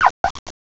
sovereignx/sound/direct_sound_samples/cries/tatsugiri_droopy.aif at master